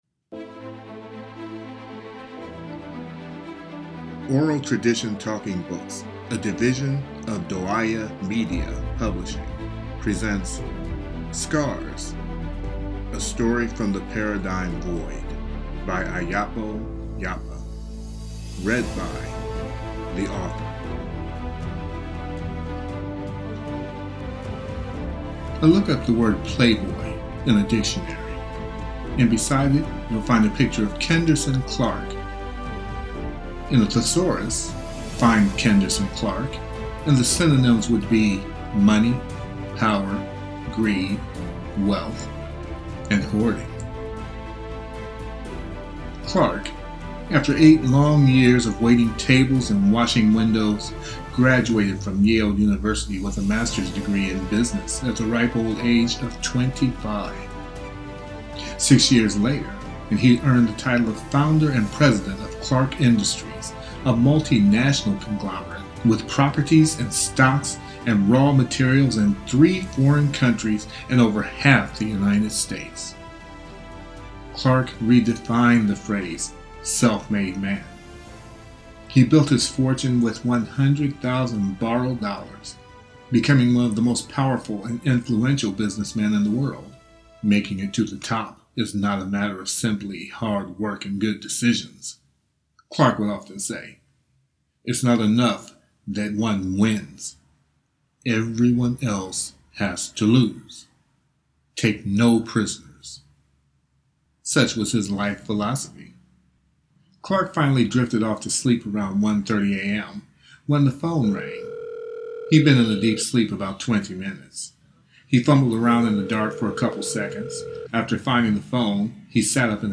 Scars_Audiobook_Excerpt
scars_audiobook_except_new_fxmp3.mp3